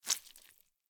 tbd-station-14/Resources/Audio/Effects/Footsteps/slime2.ogg at d1661c1bf7f75c2a0759c08ed6b901b7b6f3388c
slime2.ogg